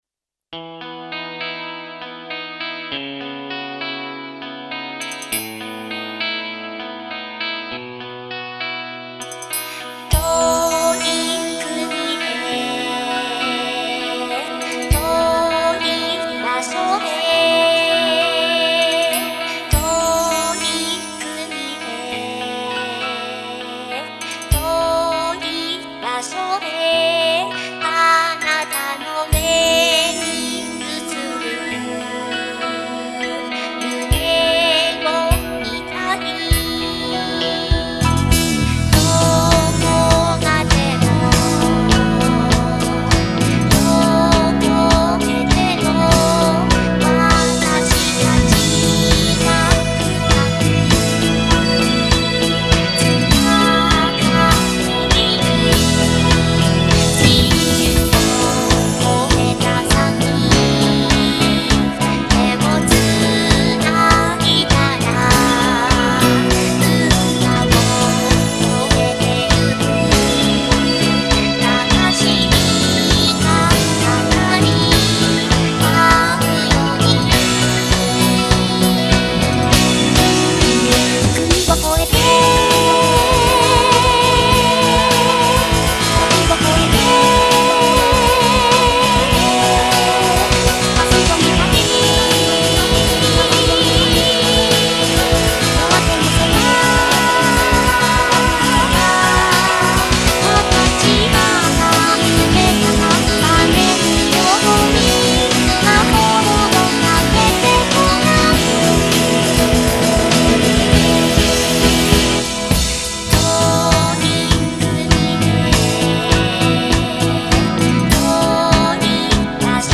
「音楽は国境を超える」をテーマにした爽やかな楽曲です。